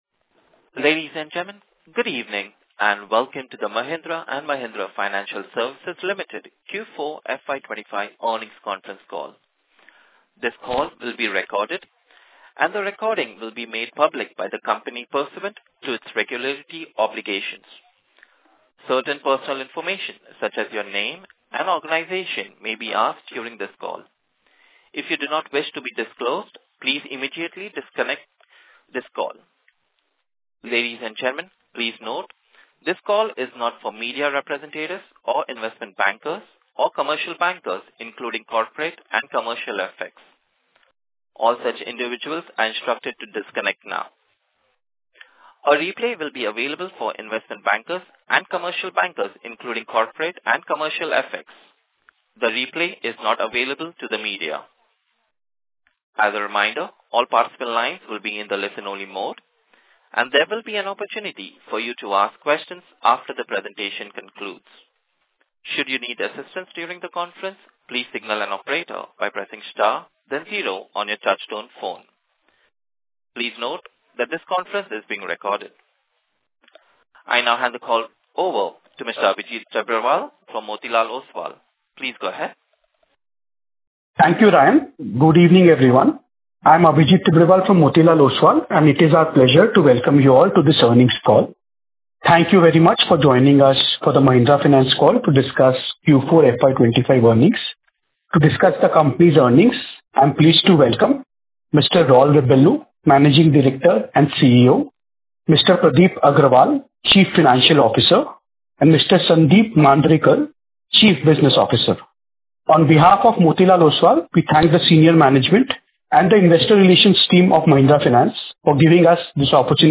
Mahindra-Finance-Q4-FY25-Concall-Audio.mp3